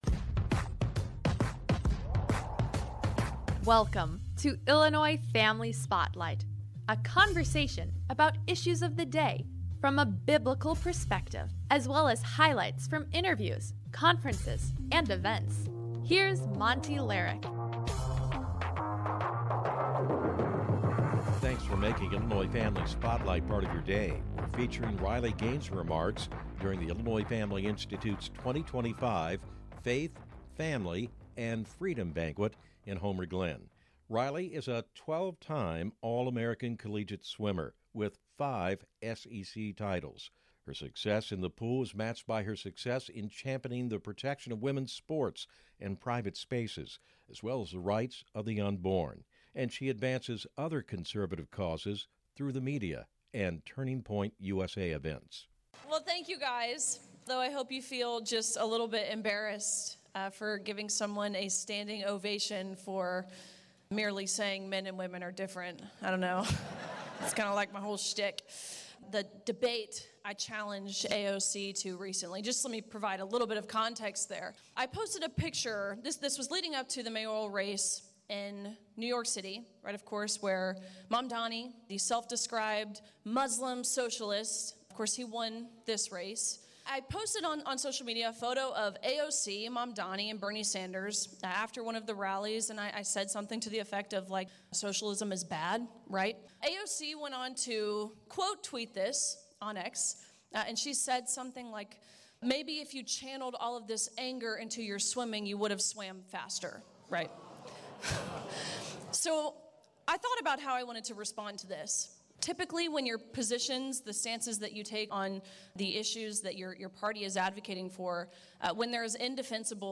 Men & Women Are Different | Riley Gaines Barker At IFI’s 2025 Banquet (IFS #462)